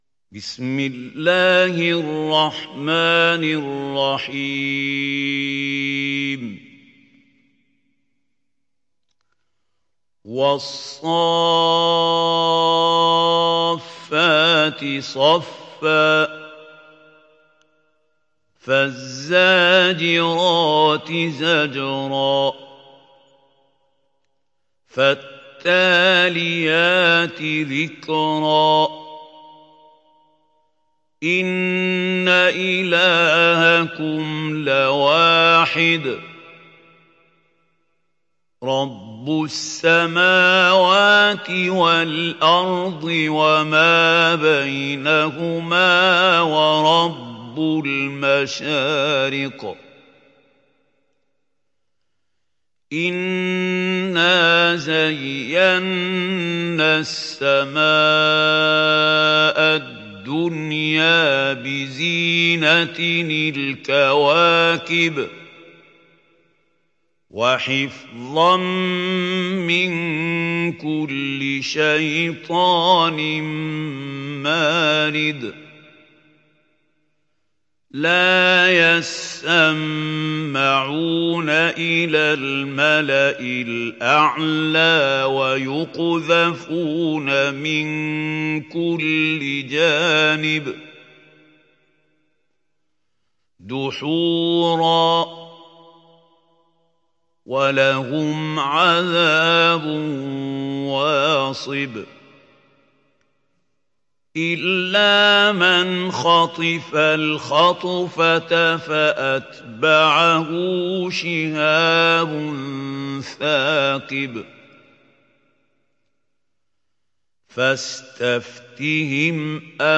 تحميل سورة الصافات mp3 بصوت محمود خليل الحصري برواية حفص عن عاصم, تحميل استماع القرآن الكريم على الجوال mp3 كاملا بروابط مباشرة وسريعة